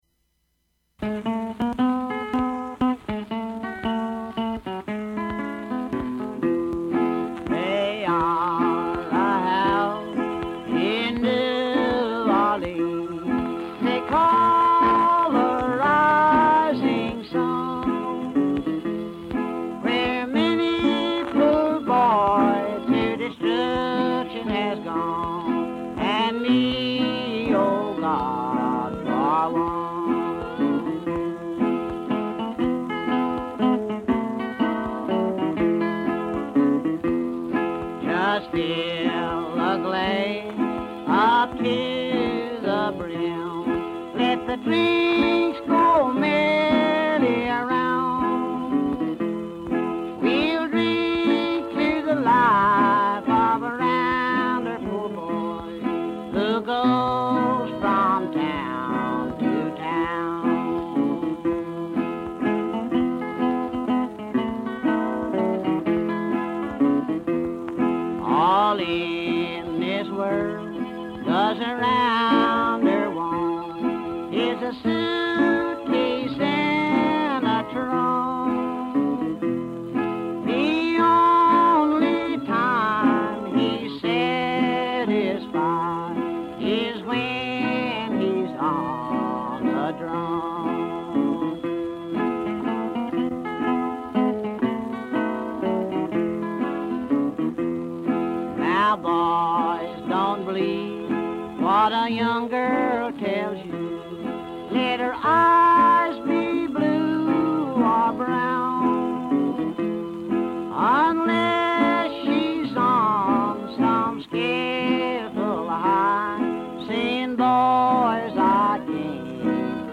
singer/guitarist
harmonica